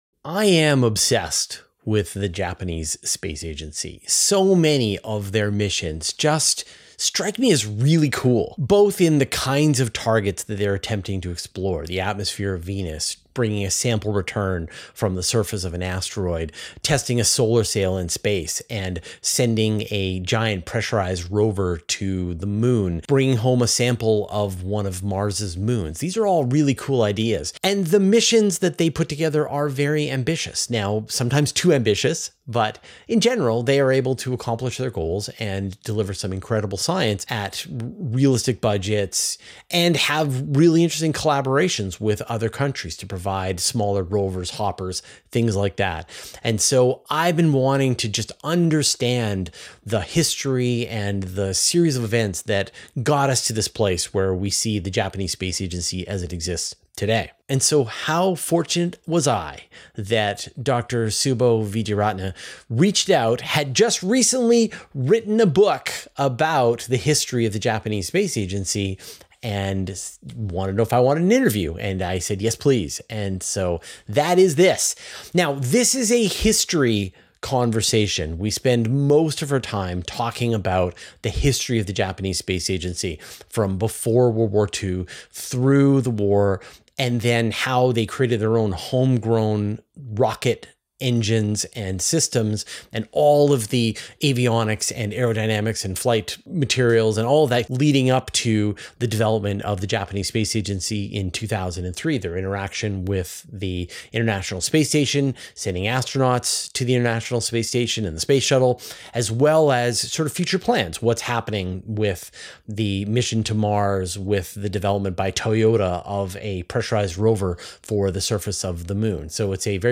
In this interview, we trace JAXA’s origins and the decisions that defined it.